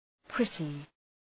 Προφορά
{‘prıtı}